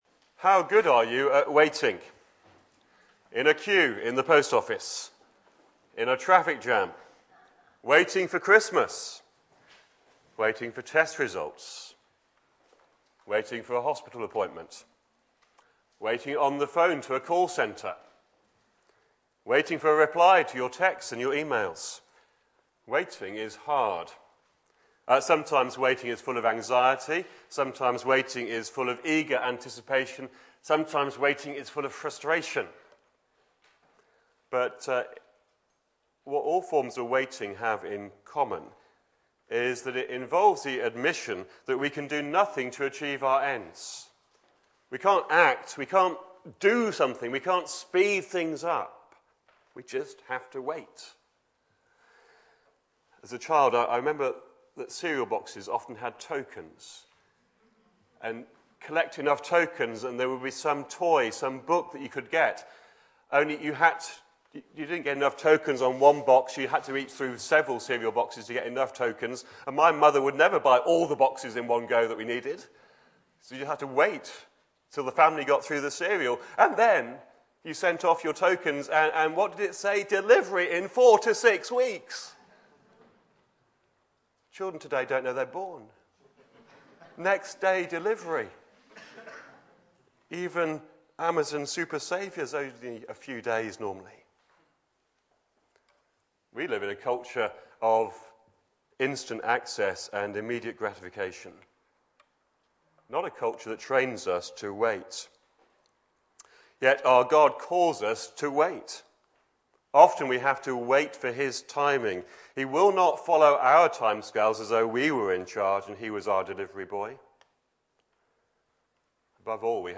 Back to Sermons Isaiah 26:1 to 27:1